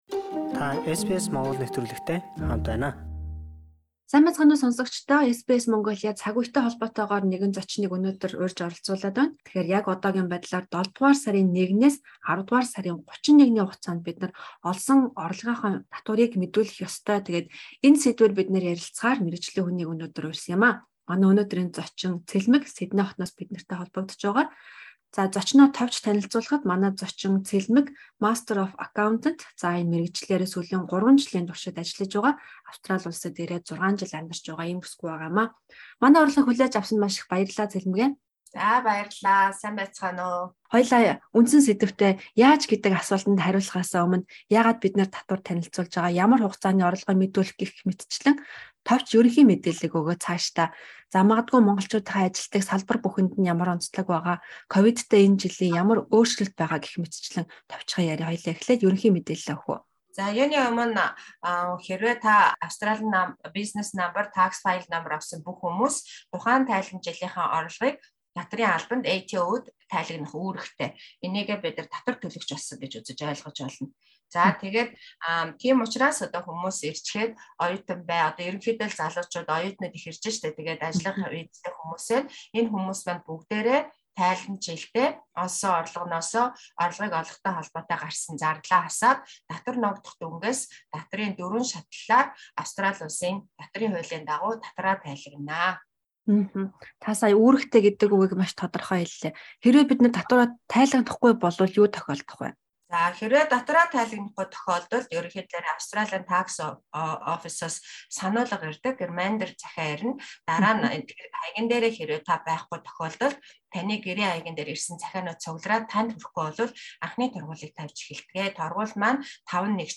Та энэ ярилцлагыг сонссоноор дараах асуултуудад хариулт авна: